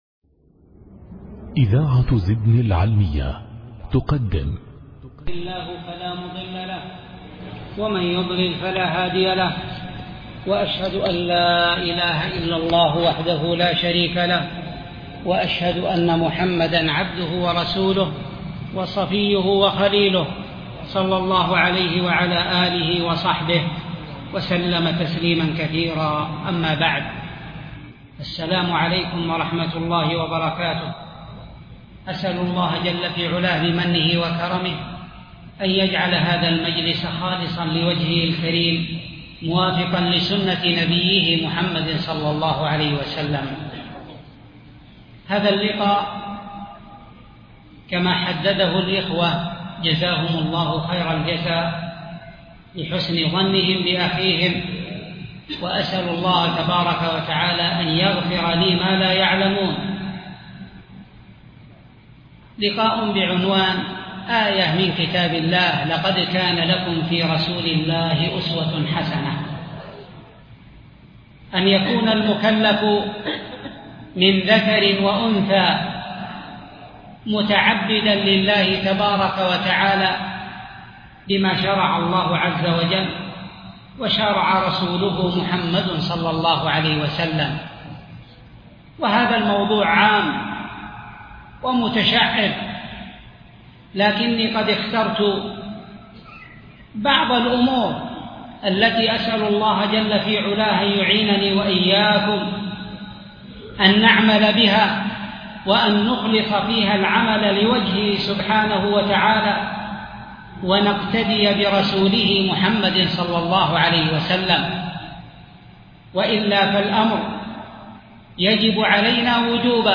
محاضرة